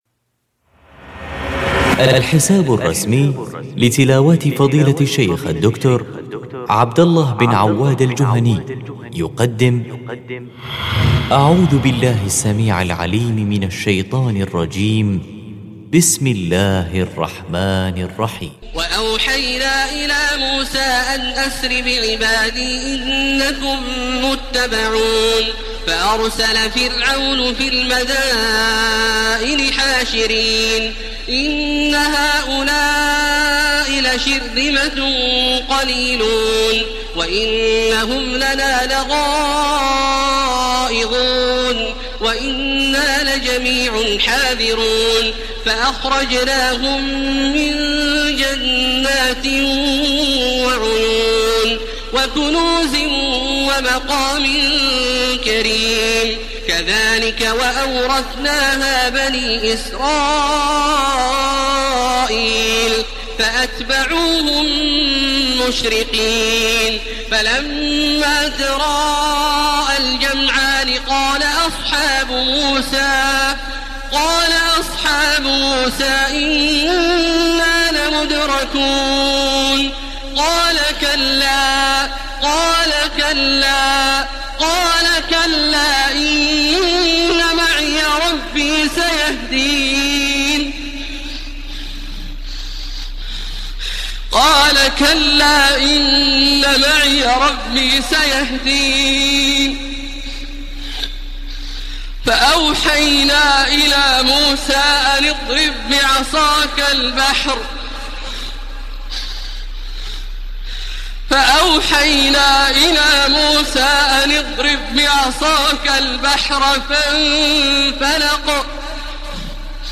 Listen online and download emotional recitation / tilawat of Surah Ash Shura Ayat or Verses from verses : 52-68 by Sheikh Abdullah Awad Al Juhany.
Sheikh Abdullah Awad Al Juhany is the Imam of Grand Mosque Mecca.